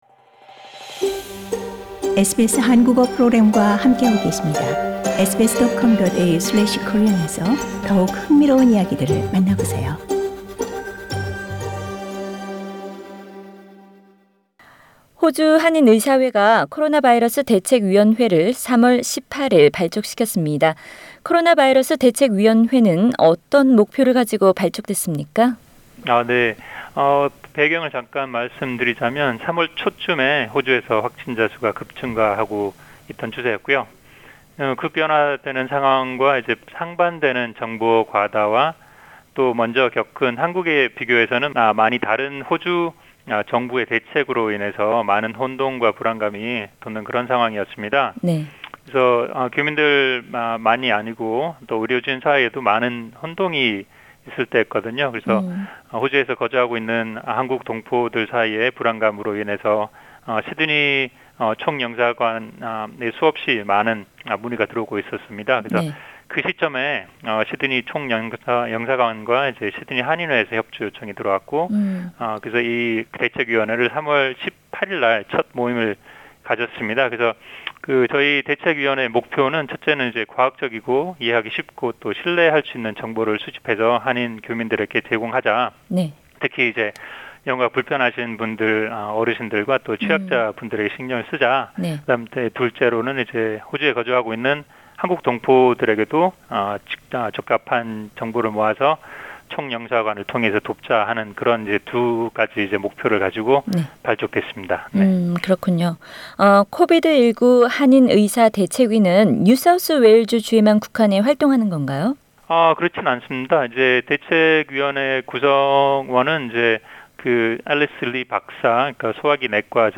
[The Full interview is available on the podcast above] Share